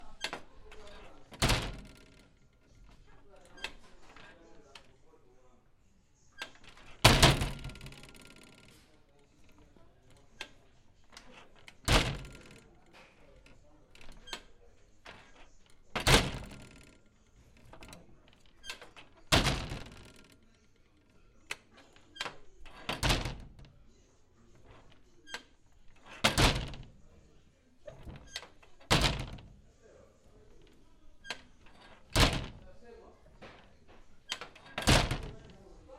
角磨机
描述：关闭mic'd角磨机自由旋转。记录在声学处理的展位。
Tag: 关闭话筒 角磨机